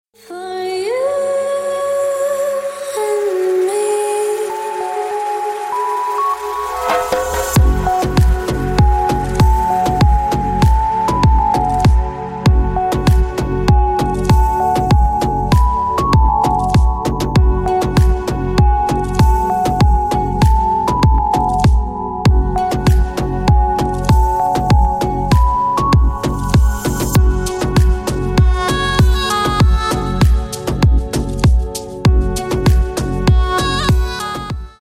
Клубные Рингтоны » # Спокойные И Тихие Рингтоны
Танцевальные Рингтоны